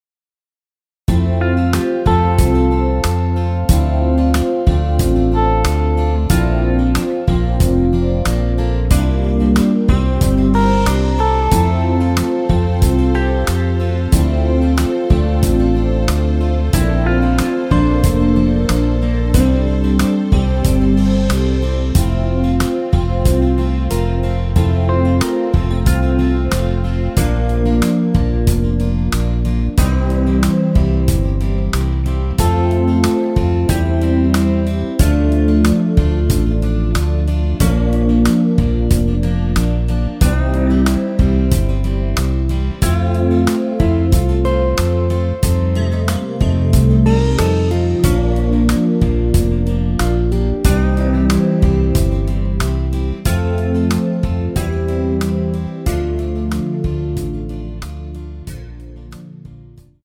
원키에서(-2)내린 MR입니다.
앞부분30초, 뒷부분30초씩 편집해서 올려 드리고 있습니다.
중간에 음이 끈어지고 다시 나오는 이유는
곡명 옆 (-1)은 반음 내림, (+1)은 반음 올림 입니다.